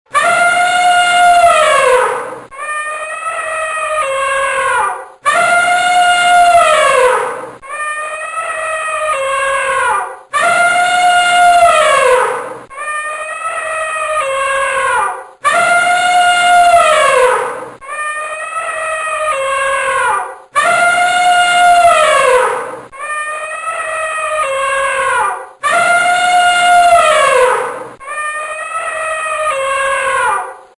코끼리의 큰소리에 묻혀서 쥐가 우는 작은 소리는 들리지 않습니다.
<들어보기 01> 코끼리 소리와 쥐 소리를 동시에 녹음한 파일
03 코끼리쥐동시 30초.mp3